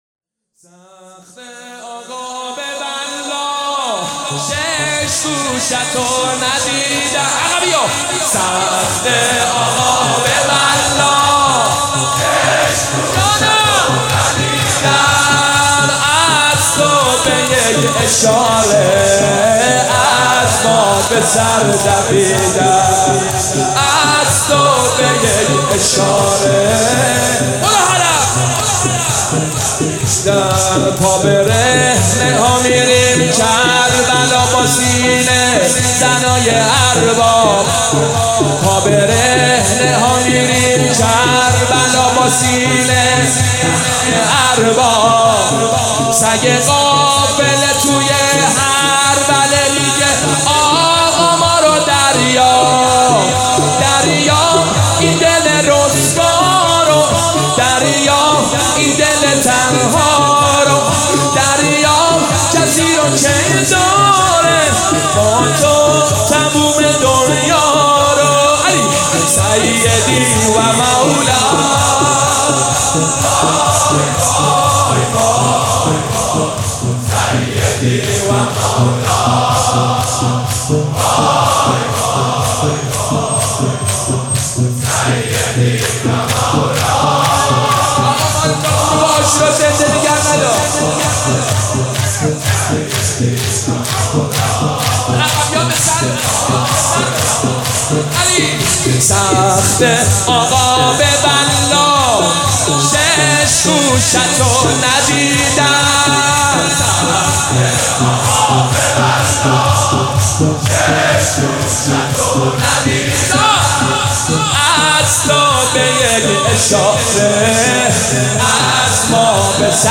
مناسبت : شب سوم محرم
قالب : شور